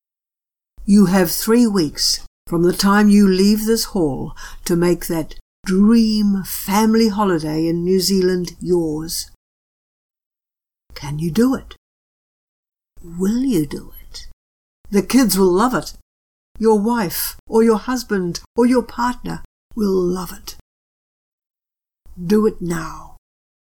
The final words are a challenge, made even stronger by the use of those two extremely effective techniques: repetition and rhetorical questions.
sample speech ending with a challenge.